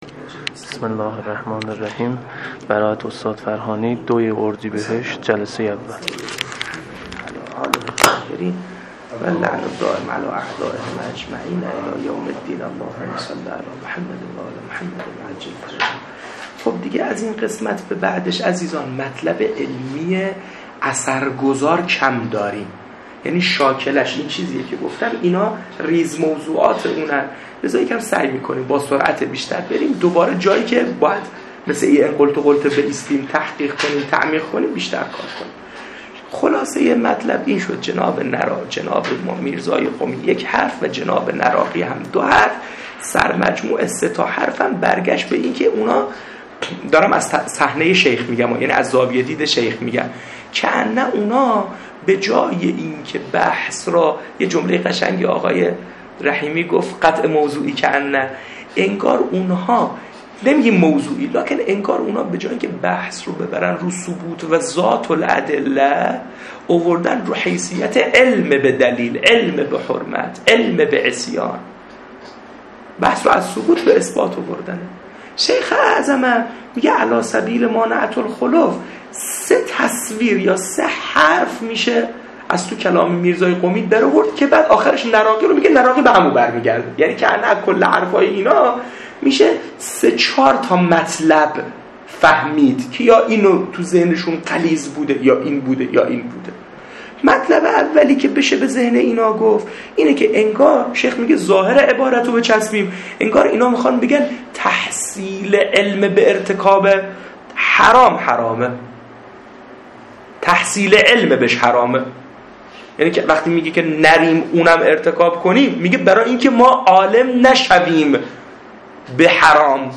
صوت دروس حوزوی- صُدا